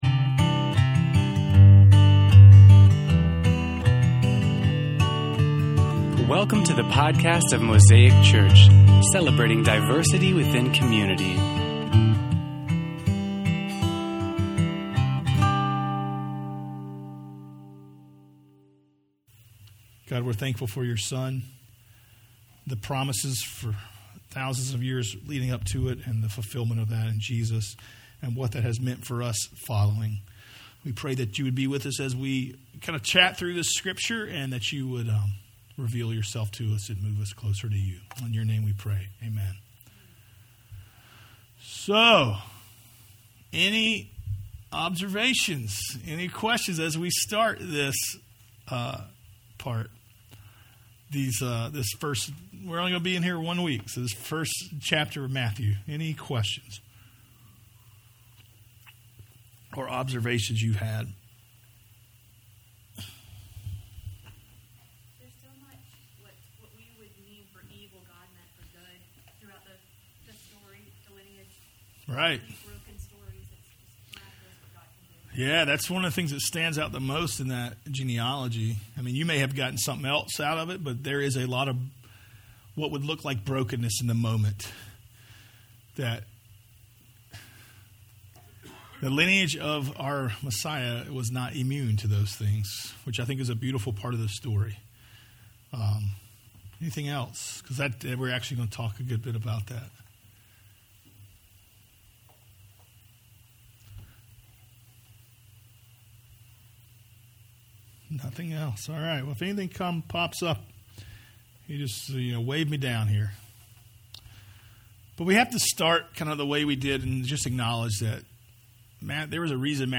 Sermon Series on Matthew's Gospel